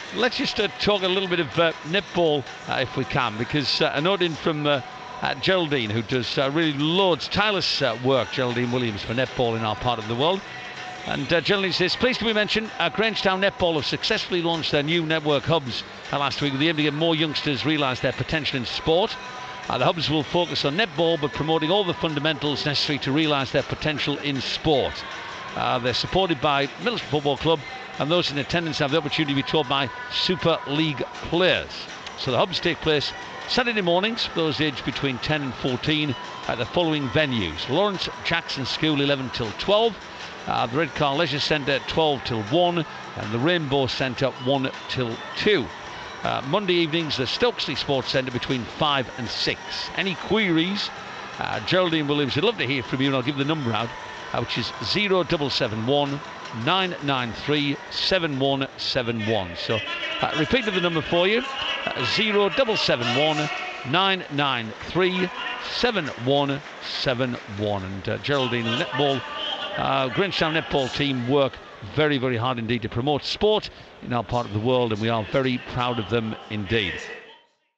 Boro Commentary